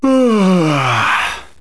gaehnen.wav